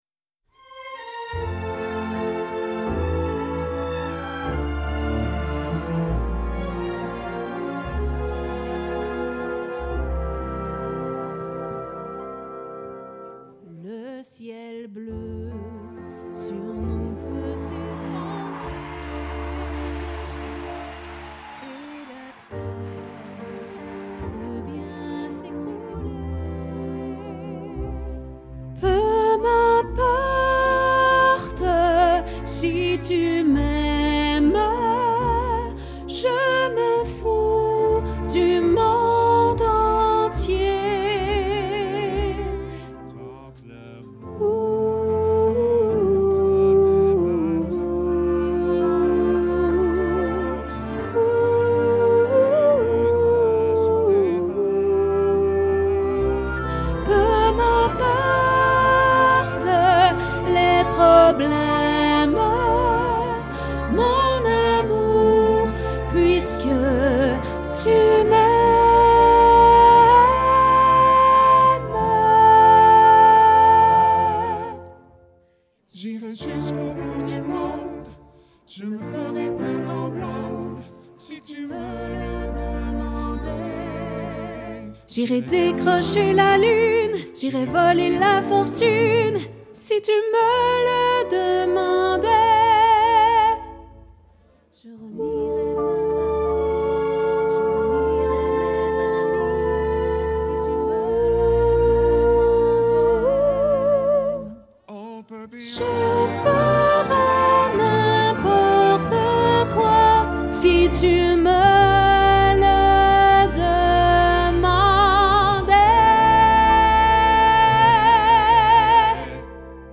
Sopranos
LHymne-a-lamour-Soprano.mp3